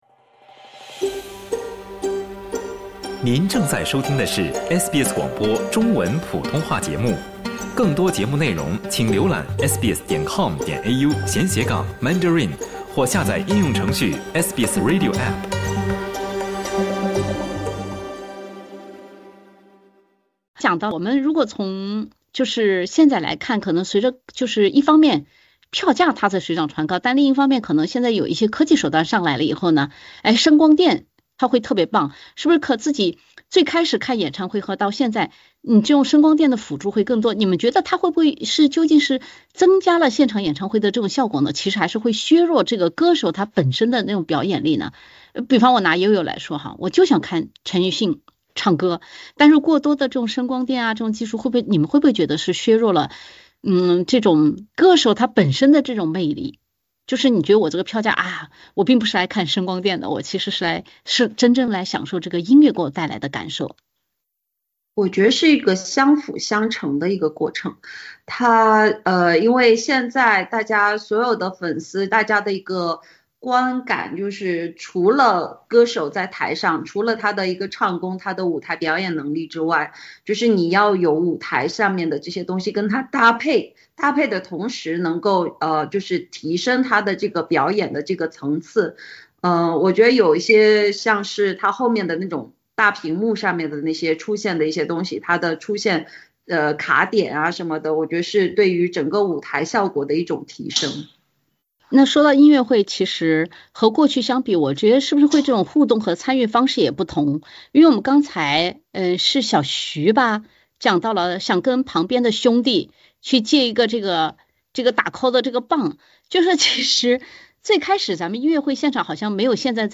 后浪嘉宾分享，多媒体效果和频繁互动究竟是让演出更有趣，还是让他们怀念过去那种纯粹的听歌时光。